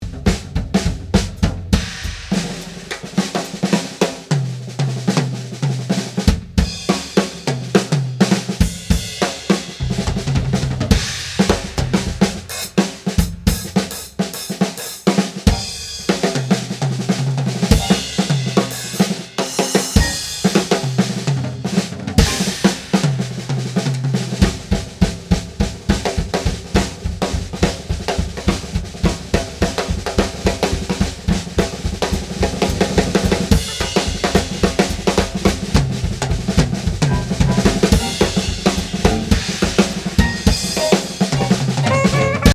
voici un petit exemple d'un D2 sur la Caisse Claire (il n'y a pratiquement aucune correction sur l'eq et concert en extérieure)
prise standard en Jazz en 4 mics : D6 sur la GC (un peu carton, je l'avoue... :-s ), D2 sur la CC et deux KM184 pour le reste
1111batterie.mp3